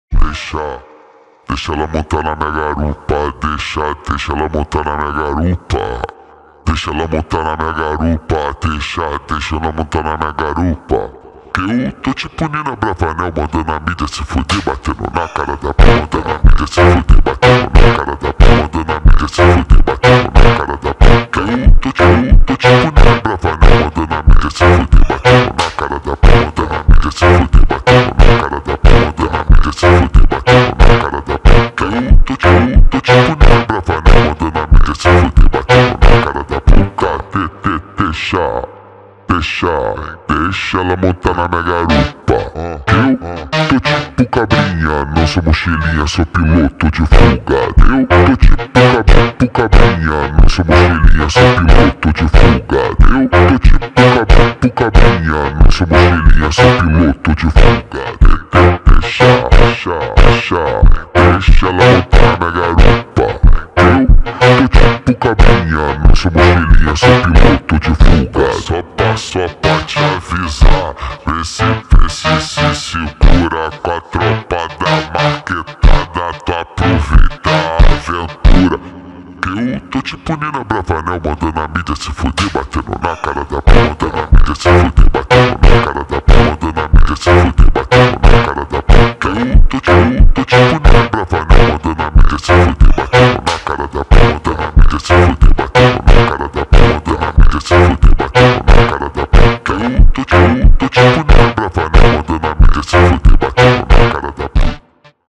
در نسخه Slowed
فانک